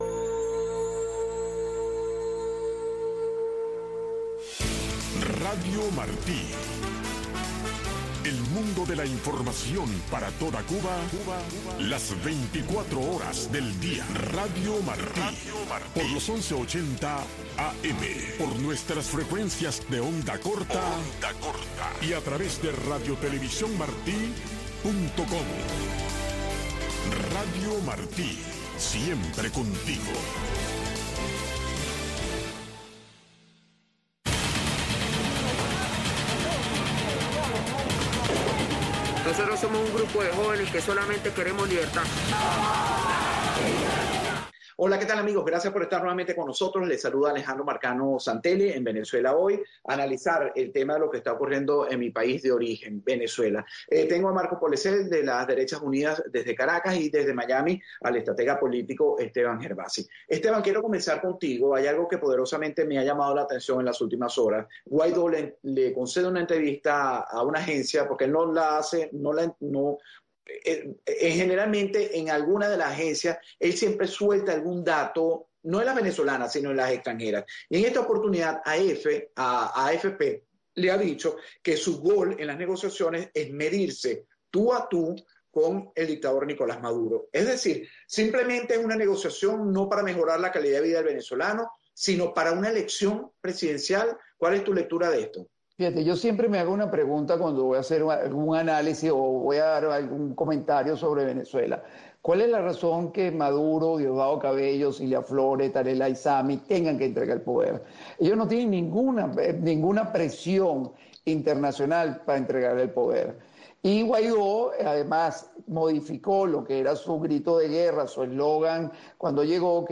Un espacio donde se respeta la libertad de expresión de los panelistas y estructurado para que el oyente llegue a su propia conclusión. De lunes a viernes a las 11:30 am, y retransmitido a las 9:00 pm.